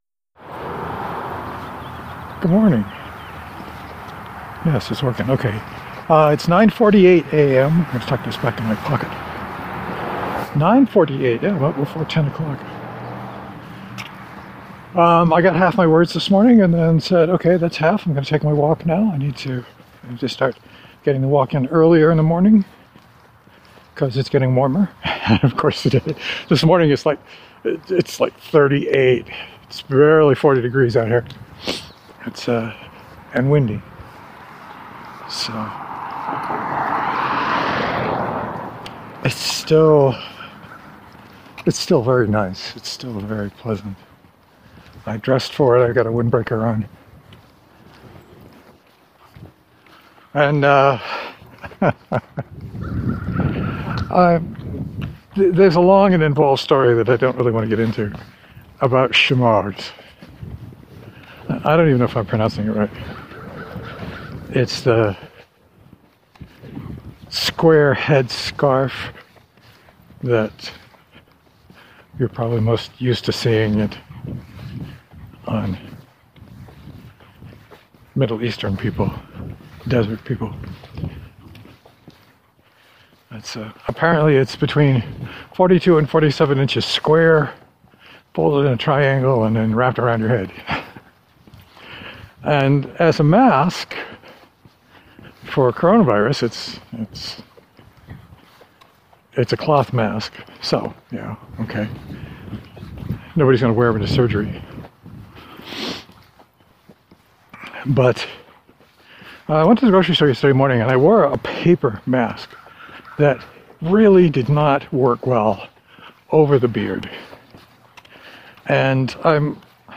I may have been a little giddy over this today.